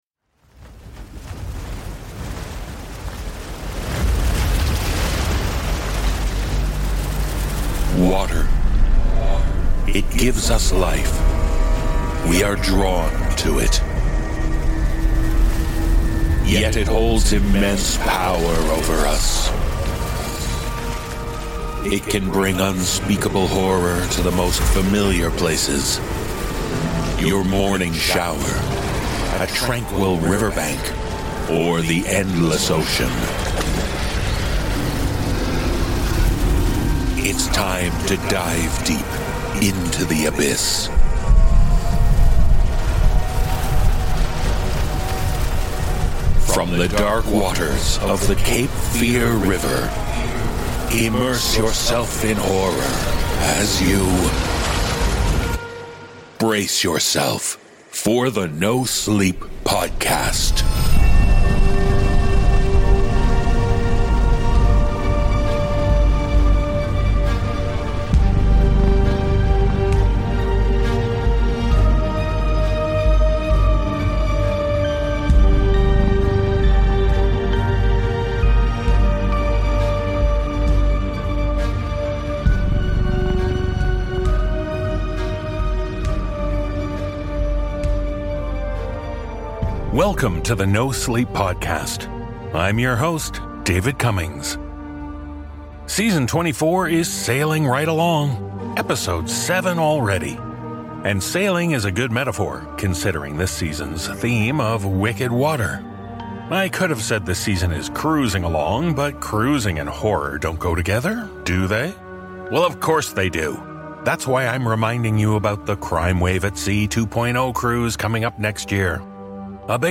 The NoSleep Podcast is Human-made for Human Minds.